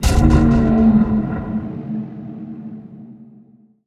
PixelPerfectionCE/assets/minecraft/sounds/mob/enderdragon/hit3.ogg at mc116